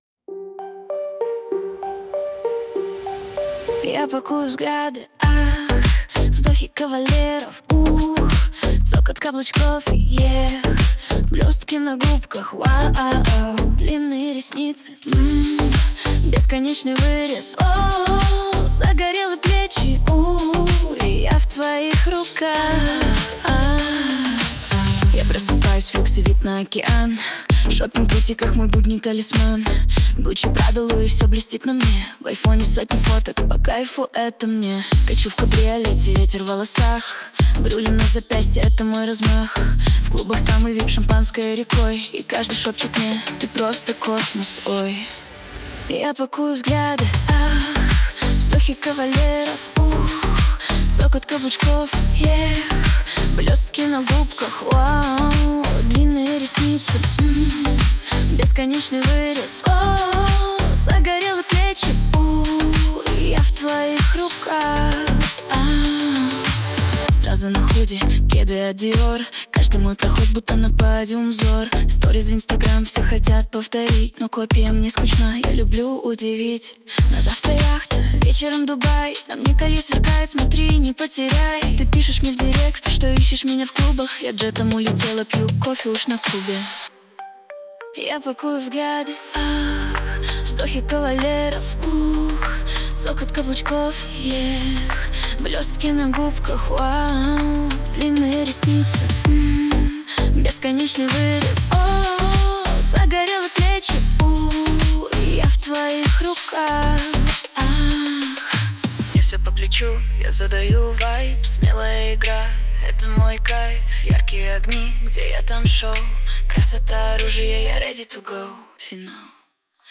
RUS, Dance, Pop, Disco | 16.03.2025 10:37